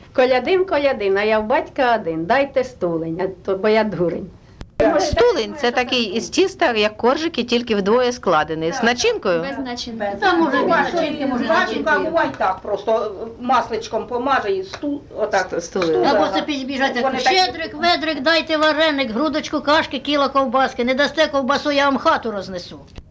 ЖанрКолядки
Місце записус. Ізюмське, Борівський район, Харківська обл., Україна, Слобожанщина